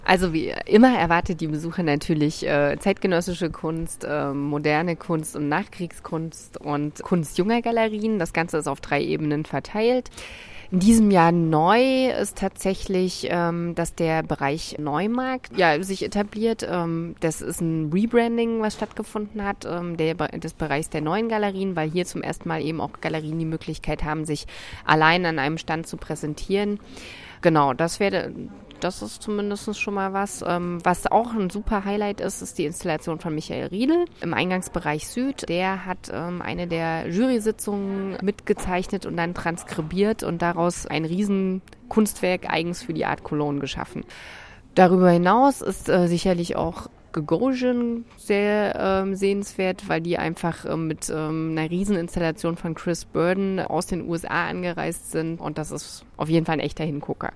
Nach der Pressekonferenz stand Sie uns für ein kurzes Interview zur Verfügung: